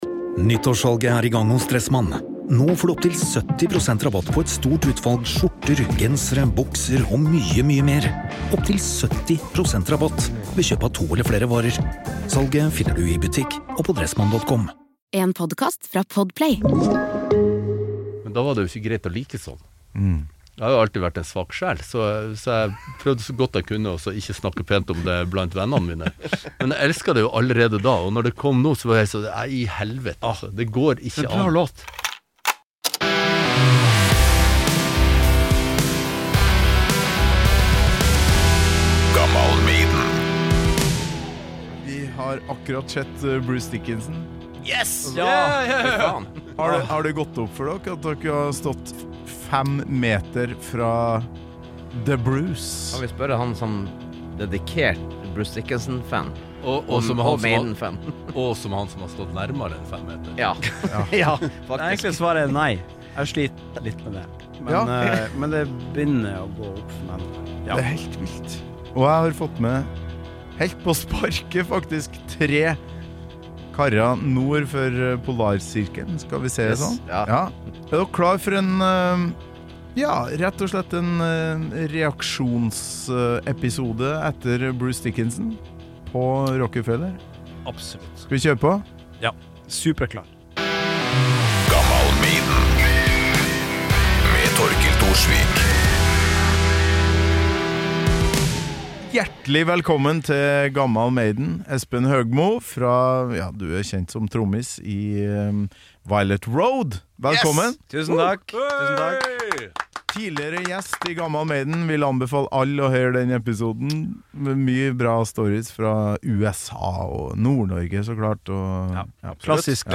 Dette opptaket ble gjort ved midnatt og stemningen er deretter.